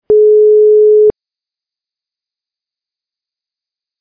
ring-back.mp3